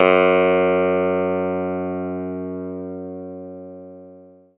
CLAVI1.03.wav